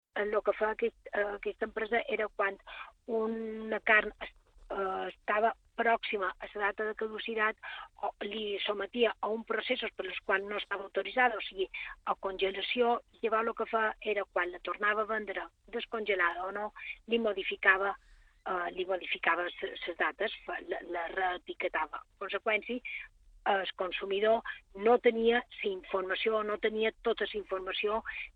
cap de servei de seguretat alimentària explica com mofidicaven l’etiquetatge a l’informatiu vespre d’IB3 Ràdio.